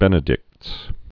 (bĕnĭ-dĭkts)